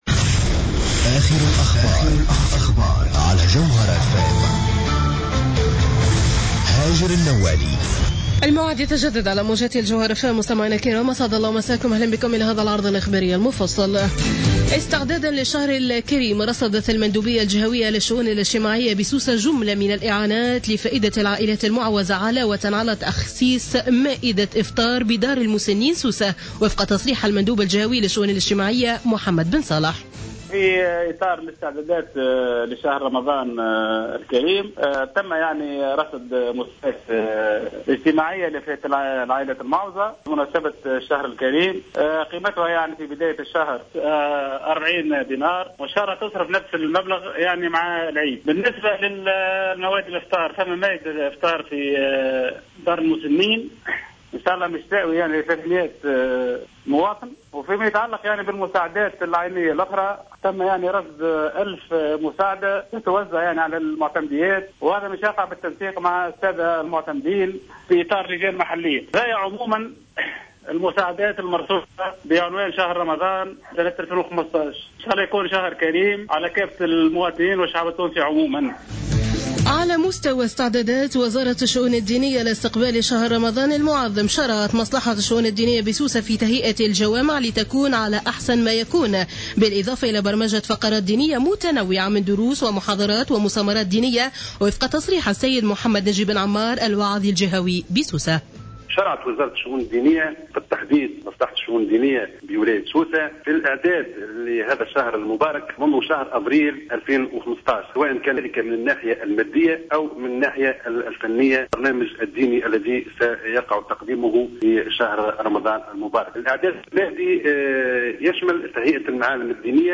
نشرة أخبار السابعة مساء ليوم الأربعاء 17 جوان 2015